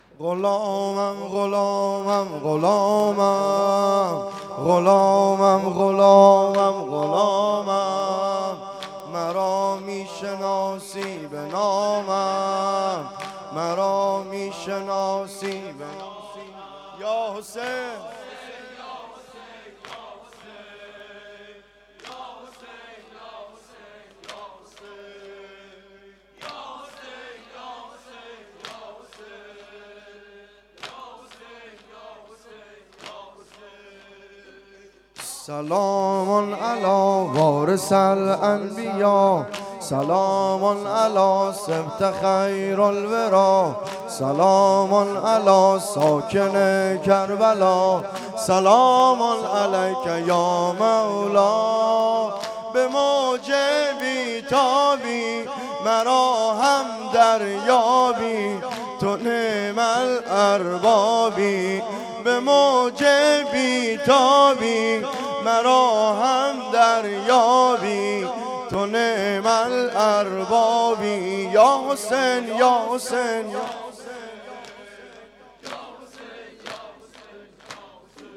واحد شور
شب دوازدهم ماه محرم